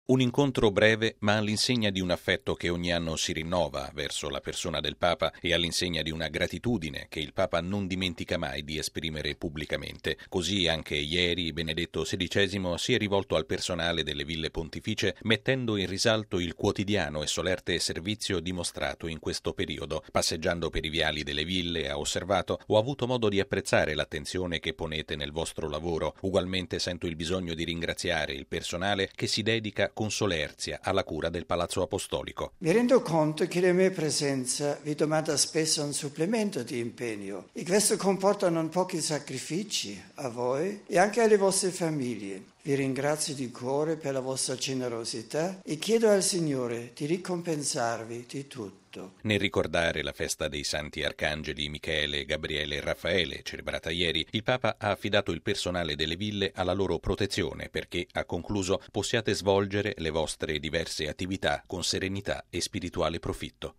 Dopo il congedo di ieri mattina dalle comunità civili e religiose della cittadina castellana, nel pomeriggio il Papa si è intrattenuto con i dipendenti delle Ville Pontificie esprimendo la propria gratitudine per la grande dedizione mostrata durante il suo soggiorno. Il servizio